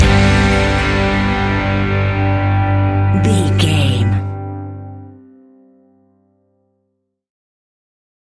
Uplifting
Ionian/Major
energetic
acoustic guitars
drums
bass guitar
electric guitar
piano
organ